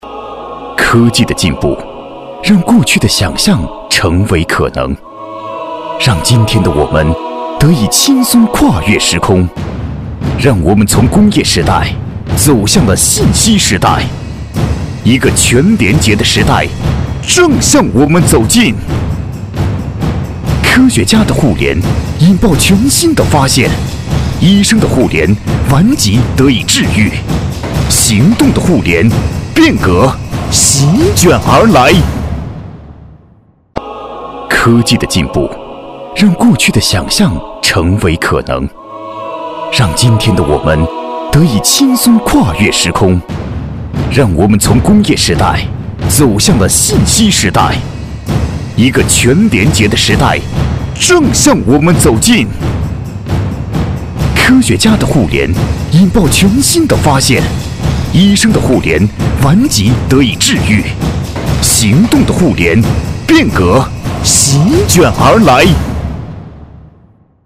国语中年大气浑厚磁性 、沉稳 、男专题片 、宣传片 、80元/分钟男S337 国语 男声 专题片-未来已来-厚重、力度、激情 大气浑厚磁性|沉稳 - 样音试听_配音价格_找配音 - voice666配音网
国语中年大气浑厚磁性 、沉稳 、男专题片 、宣传片 、80元/分钟男S337 国语 男声 专题片-未来已来-厚重、力度、激情 大气浑厚磁性|沉稳